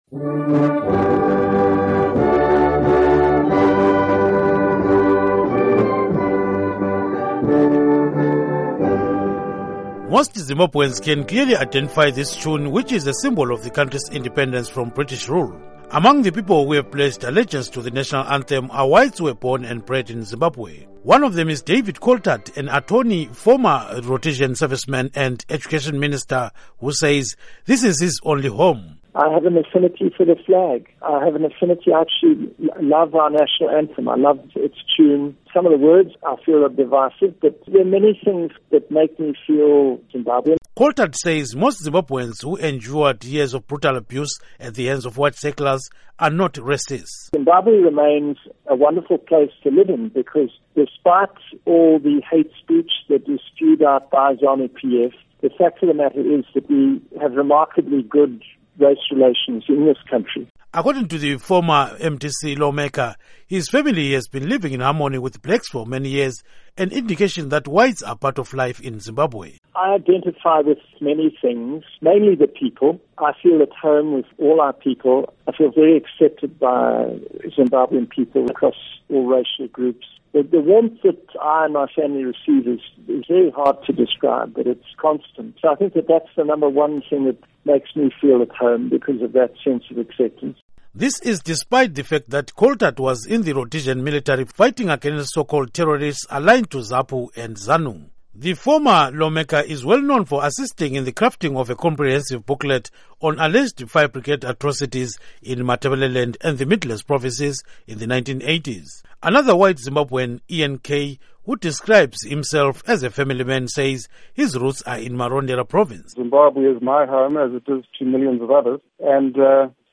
Report on White Zimbabweans